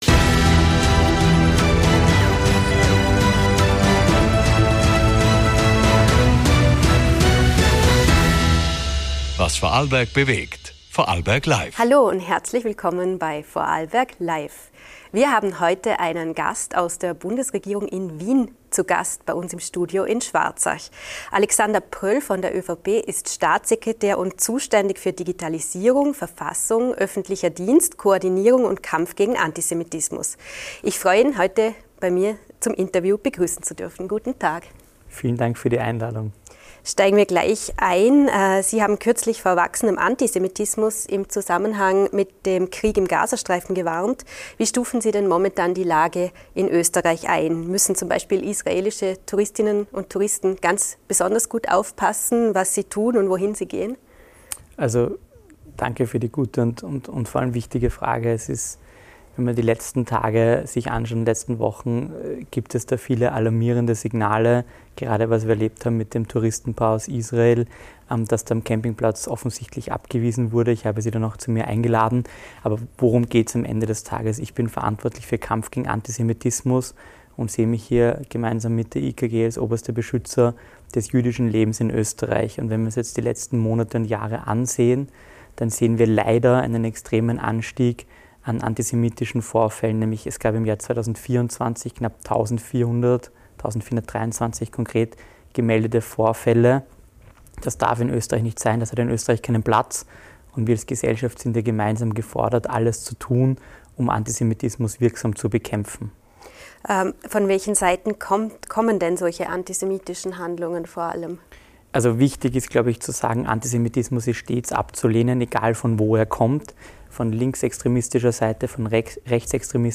Wie gestaltet sich Österreichs Weg in eine digitale Zukunft? In dieser Folge spricht Staatssekretär Alexander Pröll (ÖVP) im Studio Schwarzach mit „Vorarlberg LIVE“ über zentrale Herausforderungen und Chancen: – Der jüngste Relaunch der ID Austria als dig...